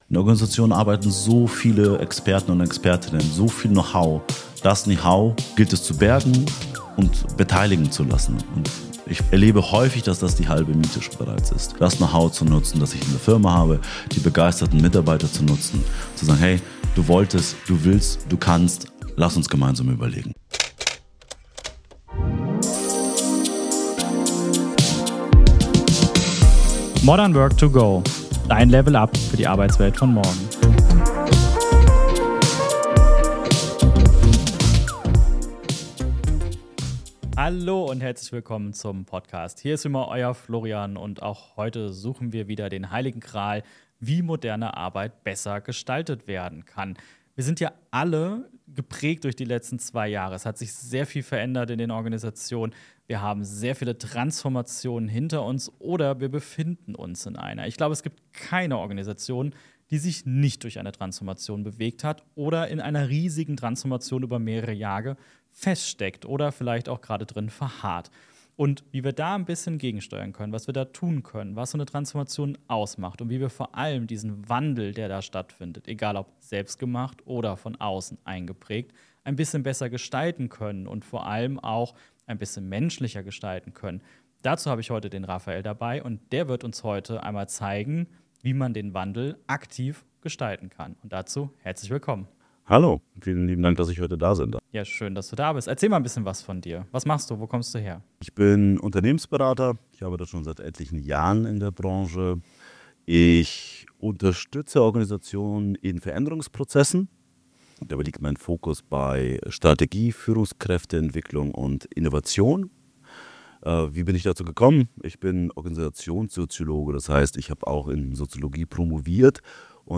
#100 Warum sich Organisationen im Wandel schwer tun - Interview